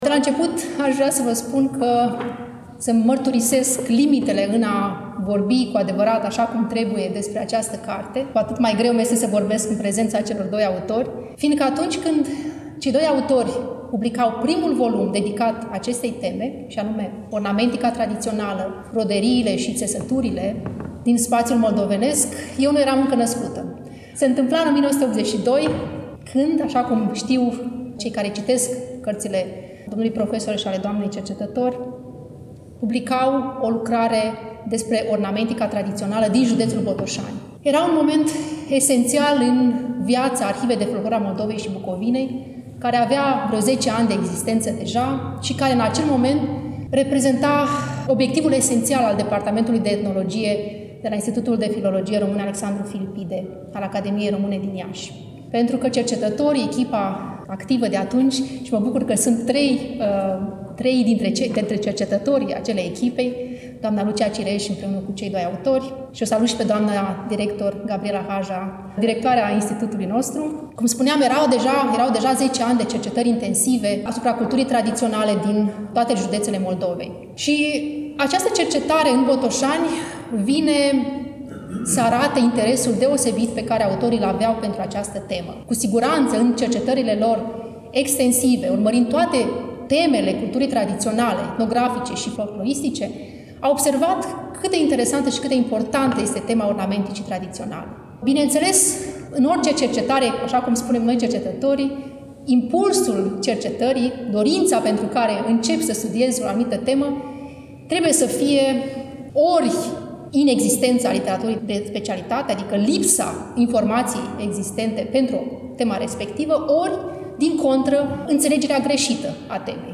Volumul a fost lansat, la Iași, nu demult, în Sala „Petru Caraman” din incinta Muzeului Etnografic al Moldovei, Palatul Culturii.